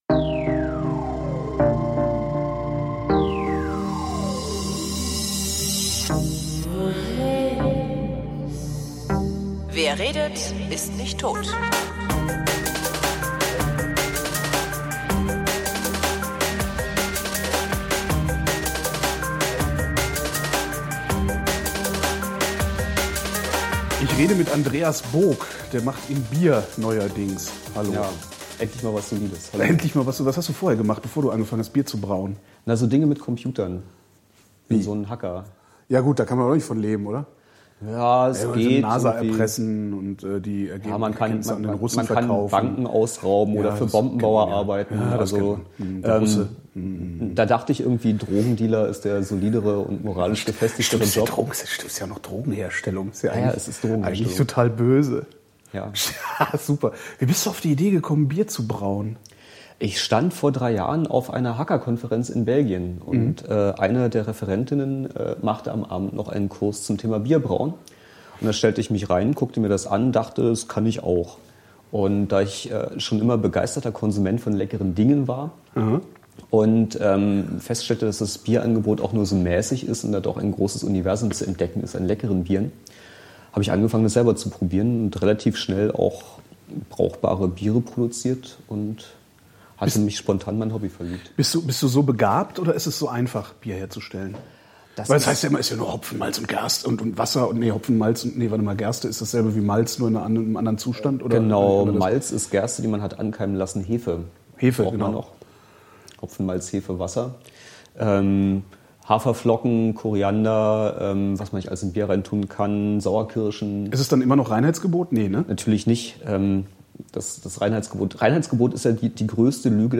Ich habe die Pausen extra drin gelassen.